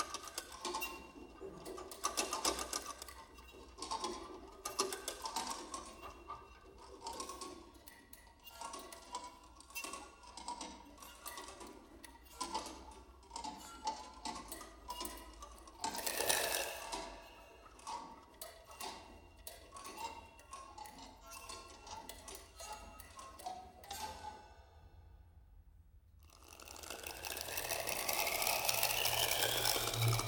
tesla_concert_2.ogg